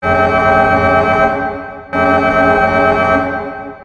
alarm.ogg